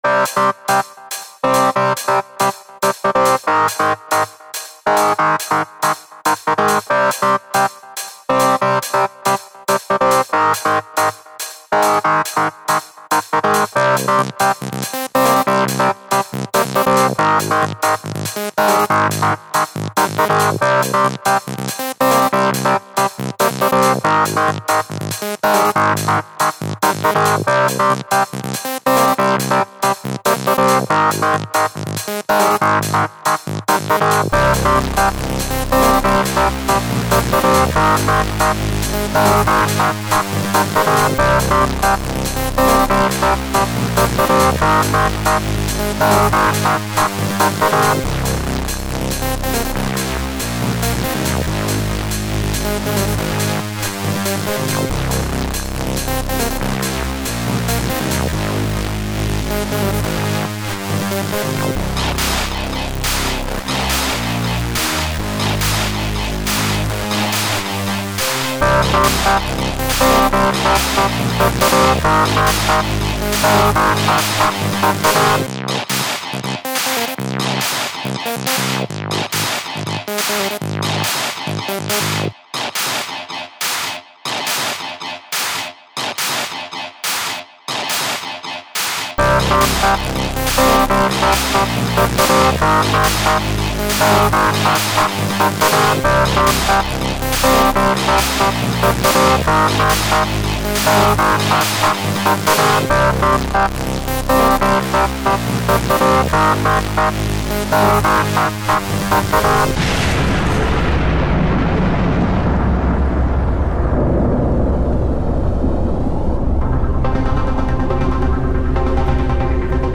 dance/electronic
Techno
Trance